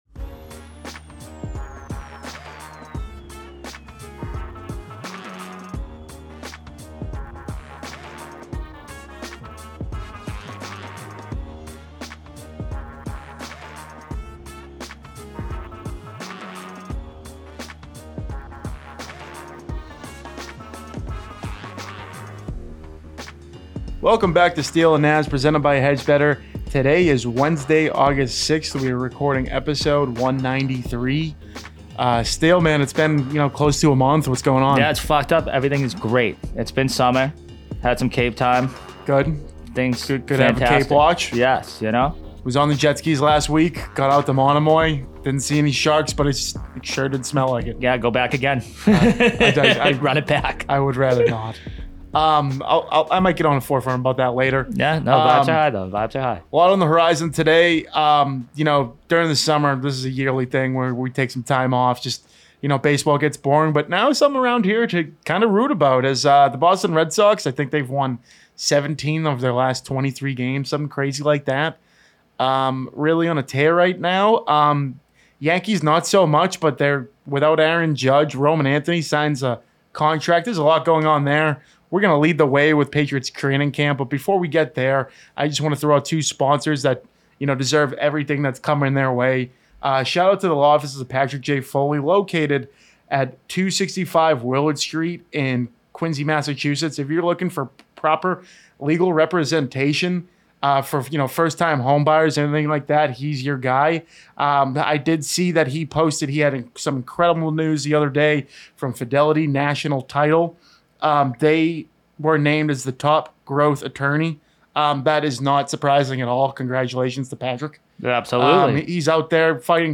ran a no-guest show, debating the following topics: